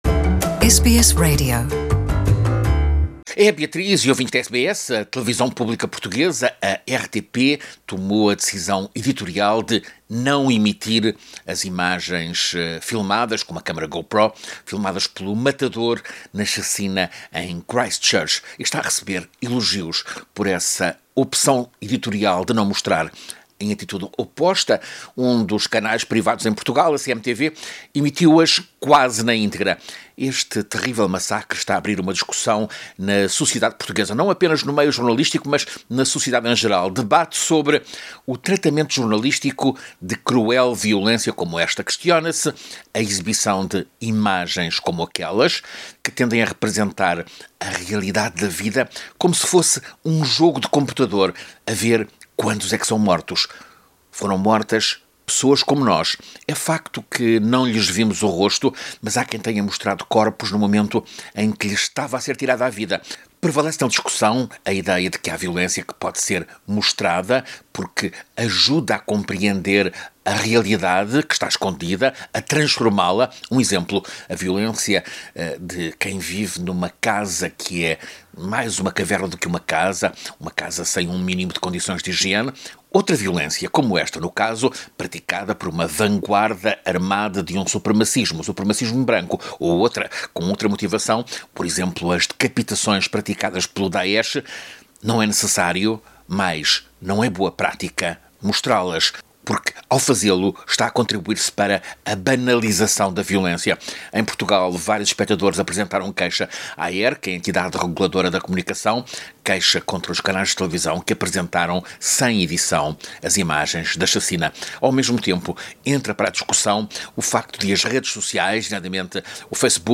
crônica